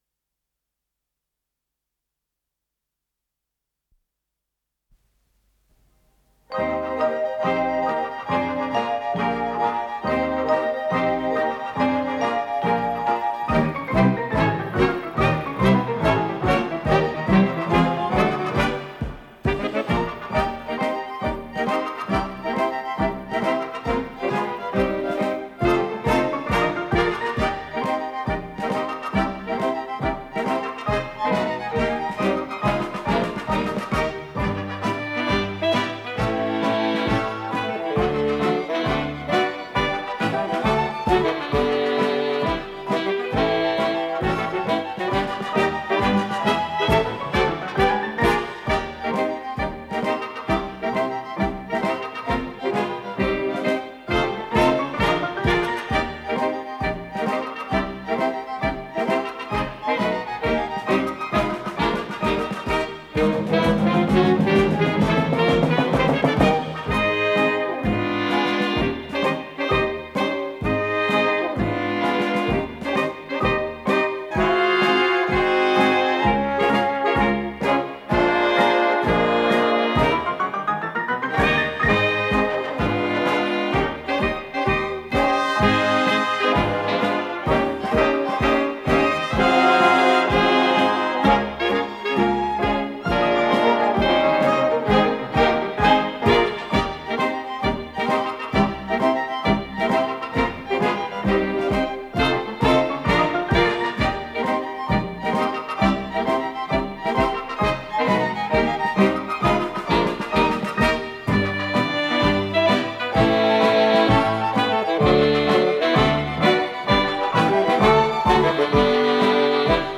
с профессиональной магнитной ленты
ПодзаголовокПьеса
ВариантДубль моно